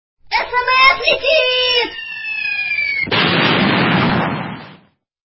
СМС летит!!! Ззззззз-БУМ!